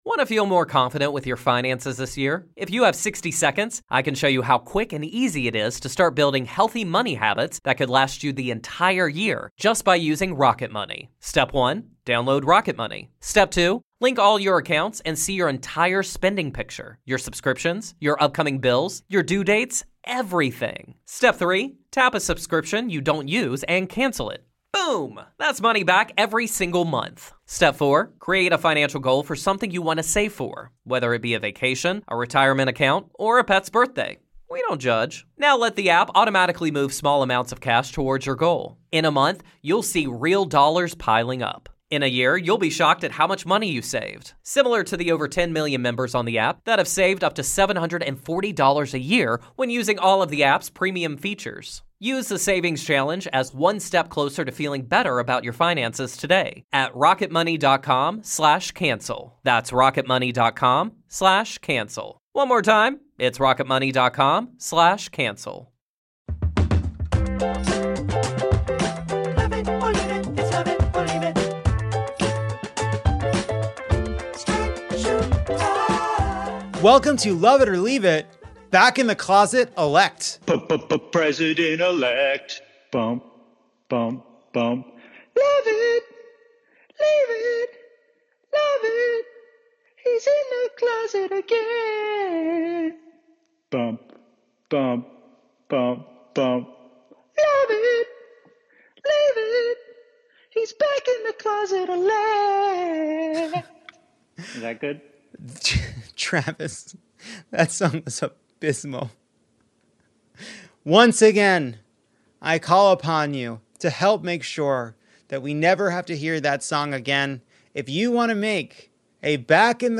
Then Pennsylvania Lt. Governor John Fetterman talks about how well the vote went in PA and historian Michael Beschloss joins to discuss the unprecedented rejection of democracy by Republicans and what happens next. Plus listeners share high notes and join to play a game as Georgia heads into two make-or-break run offs.